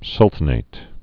(sŭltə-nāt)